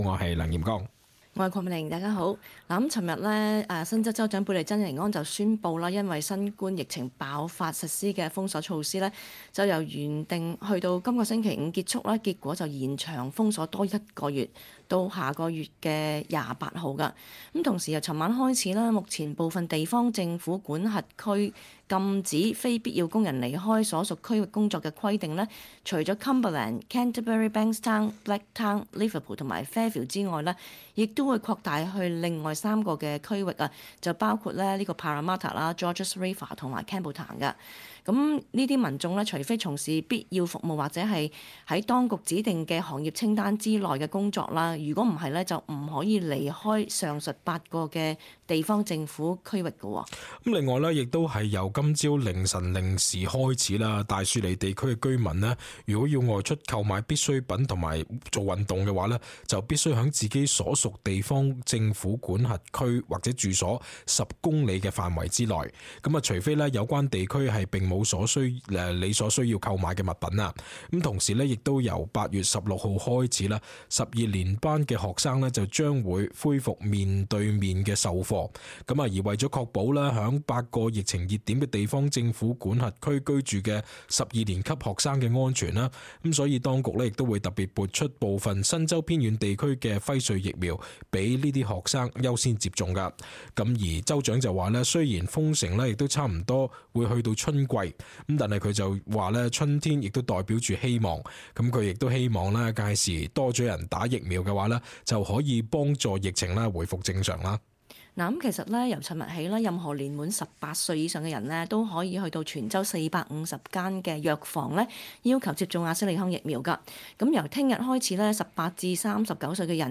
亦會解答聽眾的來電。